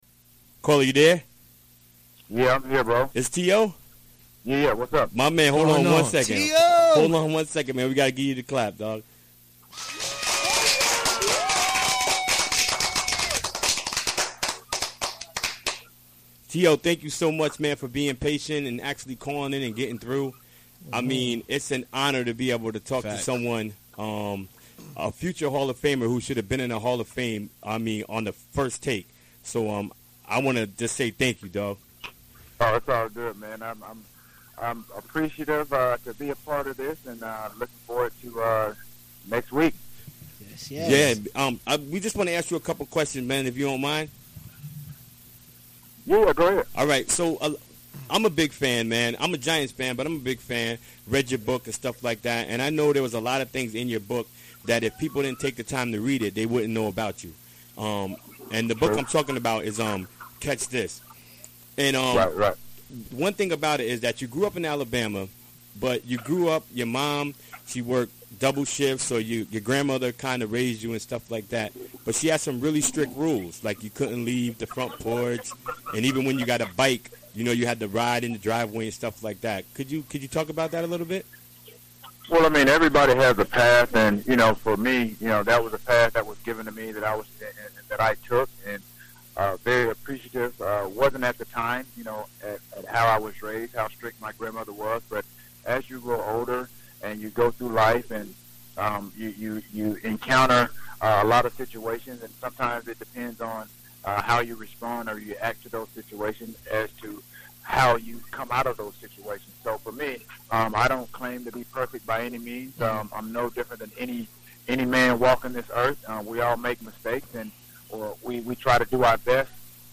NFL legend Terrell Owens calls in to talk about the upcoming Run This Town celebrity basketball game March 10 at Hudson High.
Recorded during the WGXC Afternoon Show Wednesday, March 1, 2017.